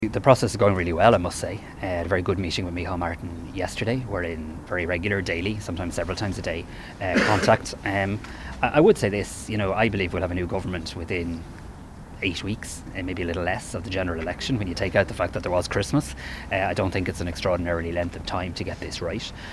Speaking today at the official opening of Craddockstown Special School in Naas, Simon Harris says talks are progressing well and are focused on policy, structure and ratification.